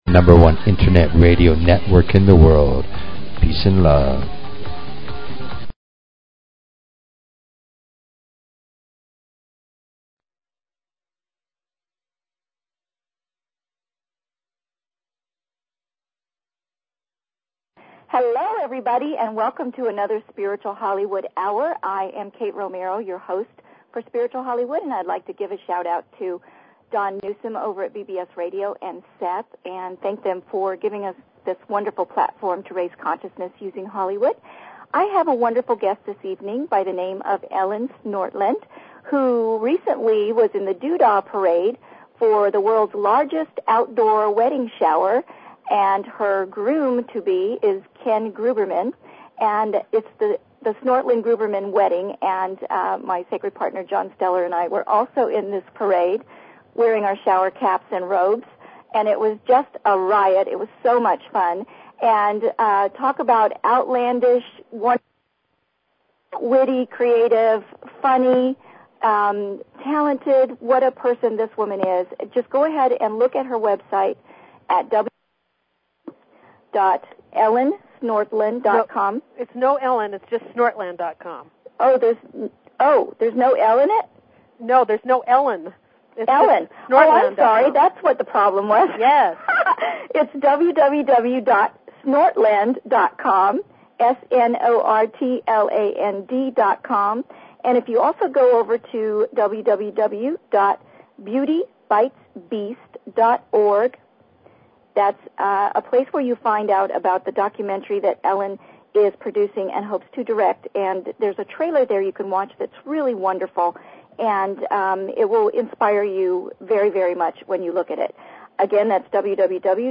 Talk Show Episode, Audio Podcast, Spiritual_Hollywood and Courtesy of BBS Radio on , show guests , about , categorized as